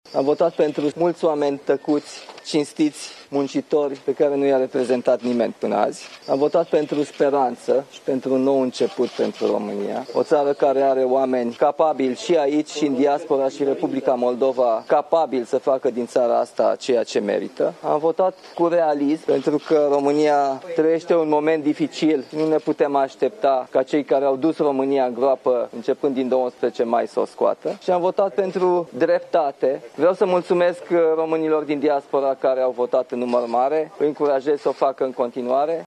ALEGERI PREZIDENȚIALE 2025, TURUL I. Nicușor Dan, la ieșirea de la urne: „Am votat pentru speranță și pentru un nou început. Am votat cu realism pentru că România trăiește un moment dificil” | AUDIO
04mai-09-Nicusor-Dan-la-vot.mp3